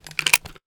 weapon_foley_pickup_08.wav